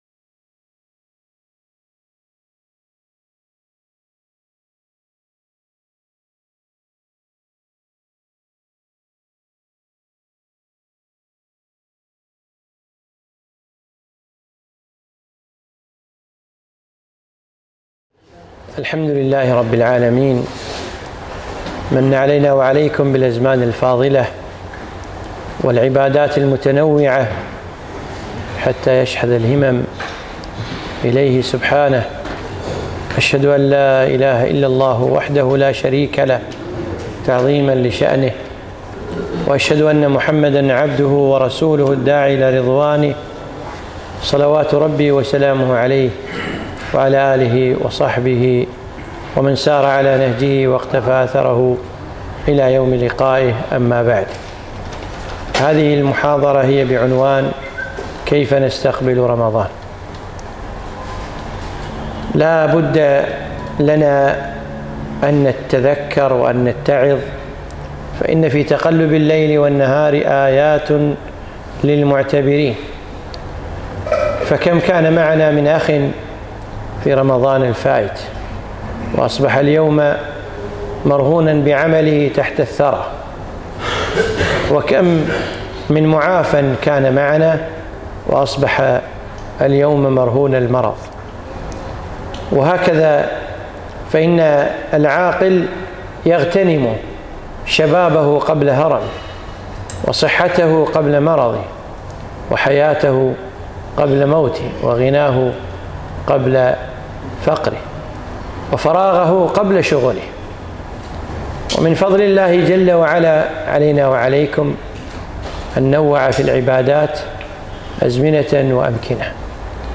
محاضرة - كيف نستقبل شهر الخيرات